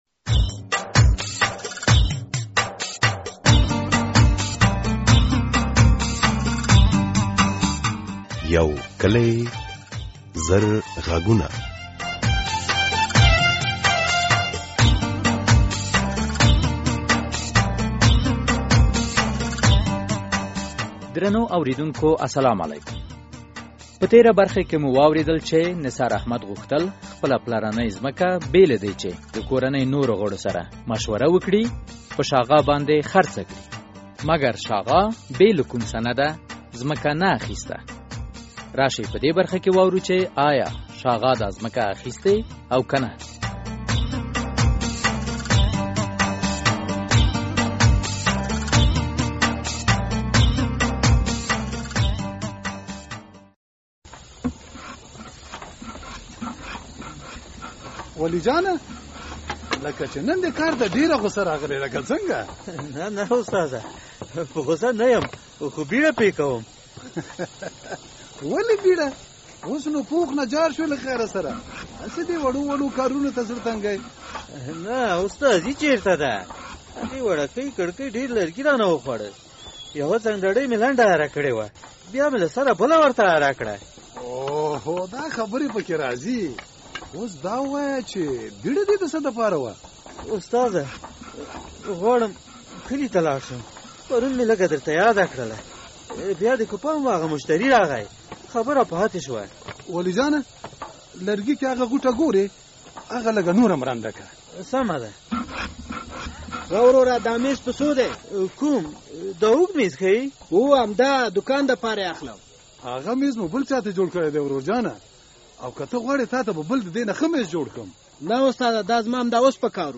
یو کلي او زرغږونه ډرامه هره اوونۍ د دوشنبې په ورځ څلور نیمې بجې له ازادي راډیو خپریږي.